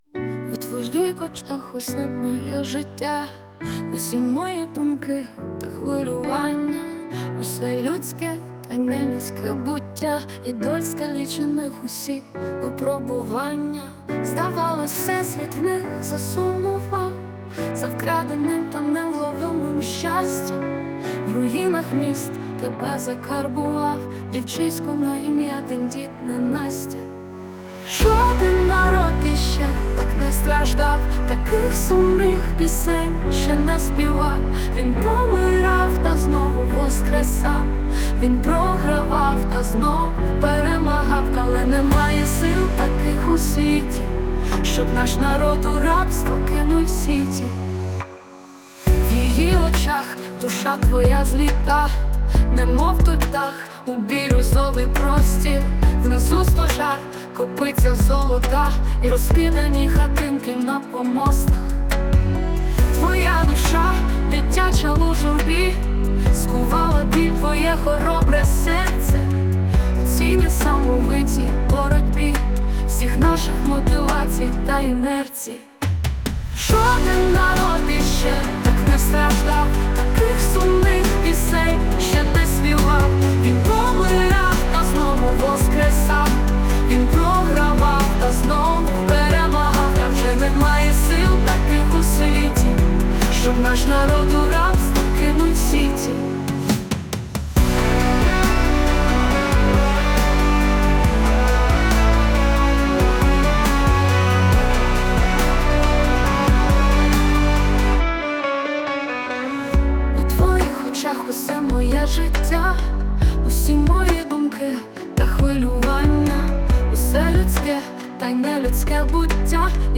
ВИД ТВОРУ: Пісня